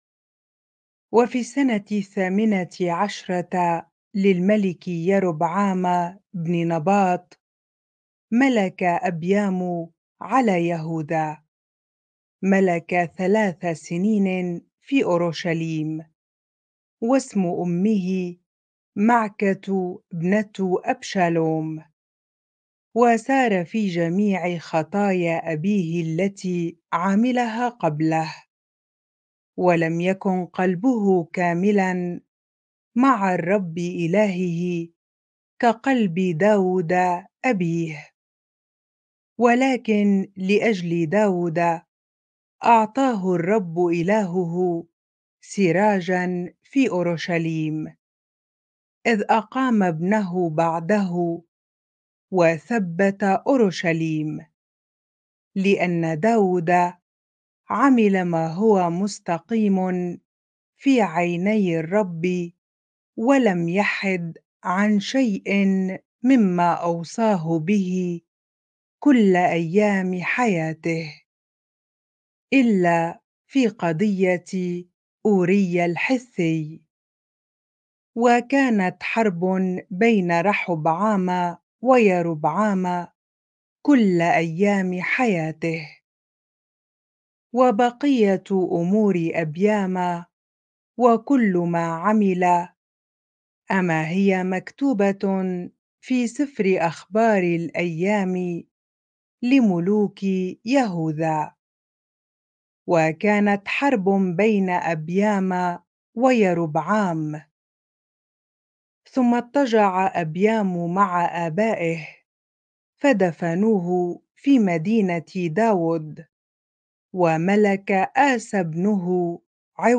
bible-reading-1 Kings 15 ar